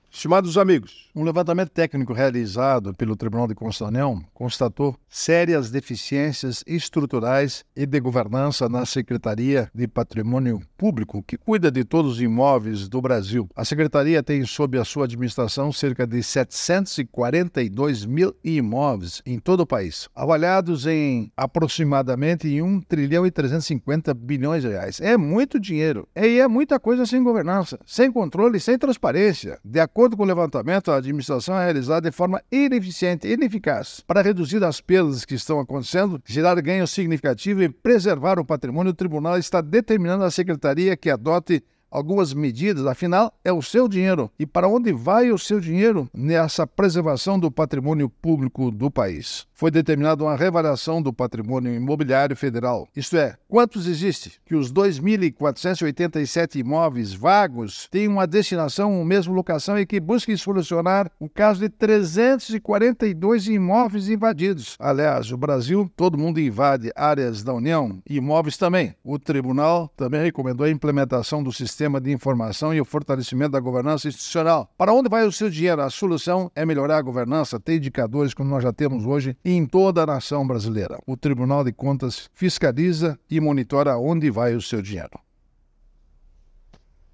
É o assunto do comentário desta sexta–feira (13/09/24) do ministro Augusto Nardes (TCU), especialmente para OgazeteitO.